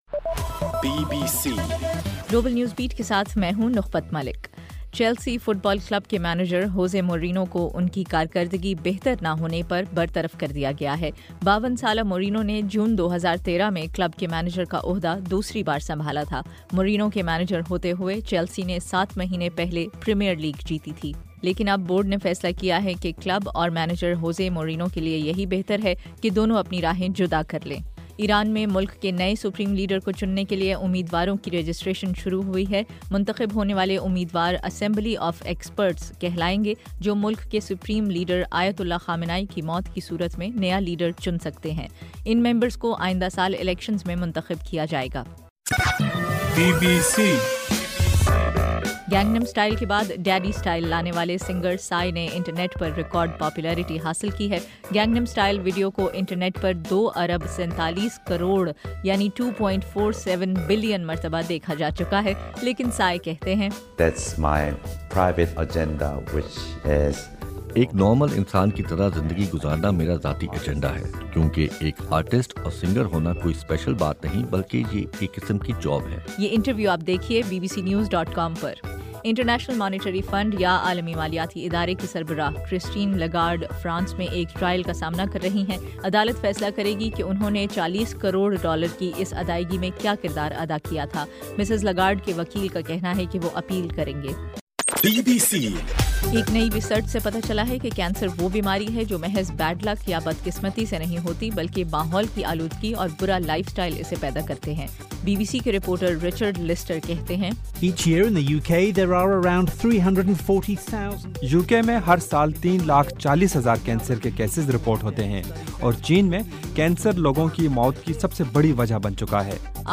دسمبر 17 رات 12 بجے کا گلوبل نیوز بیٹ بلیٹن